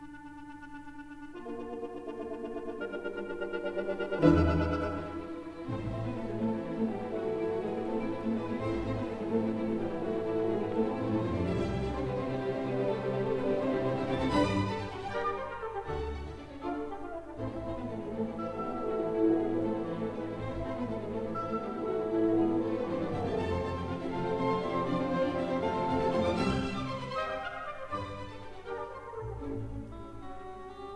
Scherzo